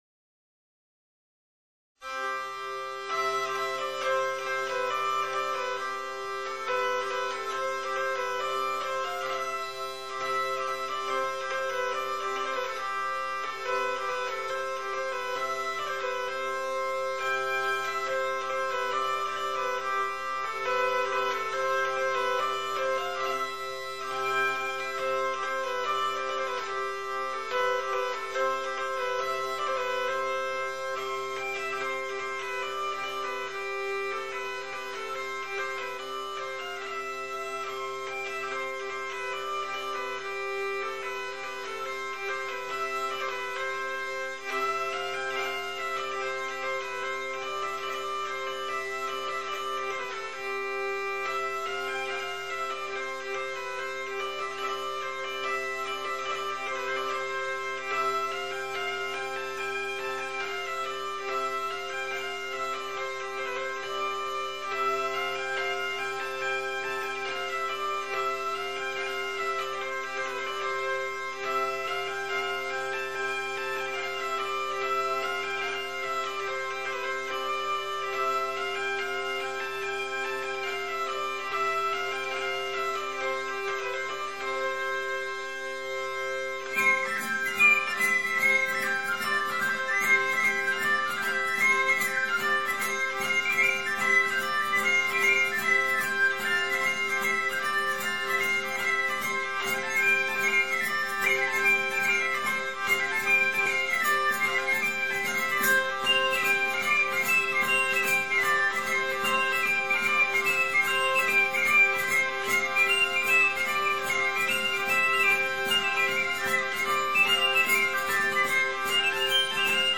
用的古樂器蠻特殊